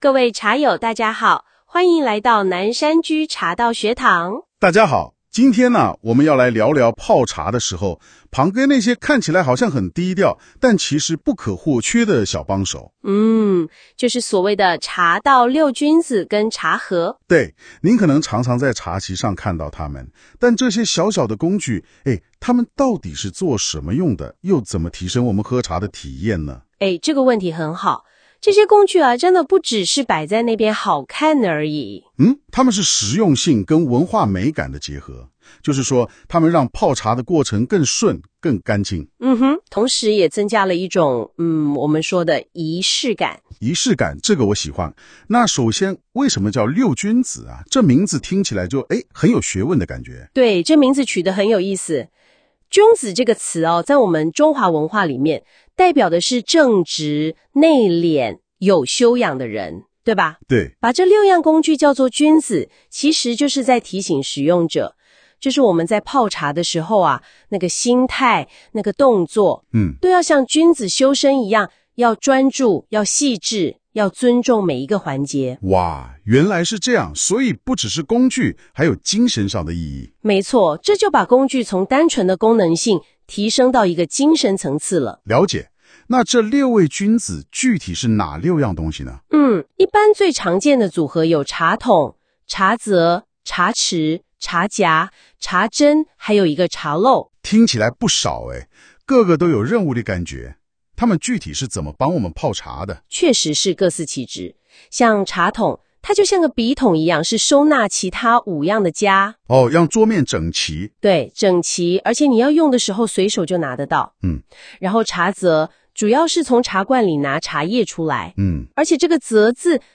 【語音導讀】茶道六君子與茶荷解析（6分38秒）